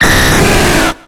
Cri de Dracolosse dans Pokémon X et Y.